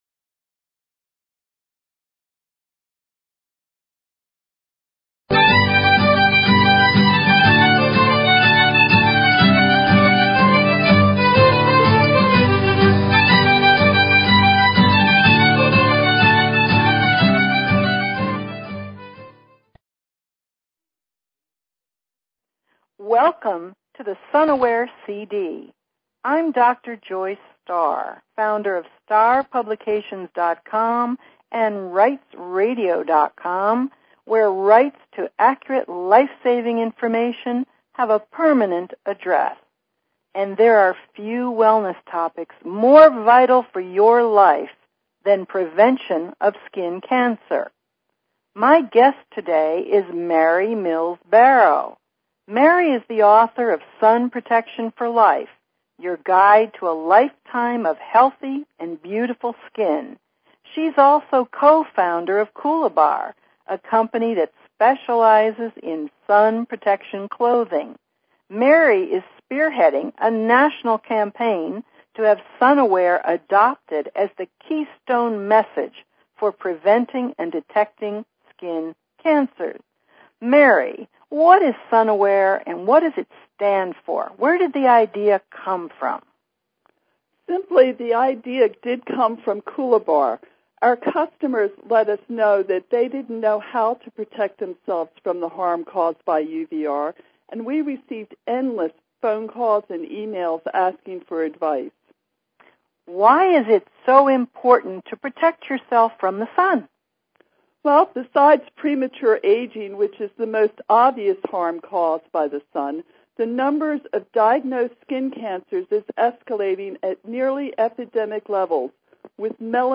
Talk Show Episode, Audio Podcast, Rights_Radio and Courtesy of BBS Radio on , show guests , about , categorized as